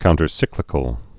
(kountər-sĭklĭ-kəl, -sīklĭ-)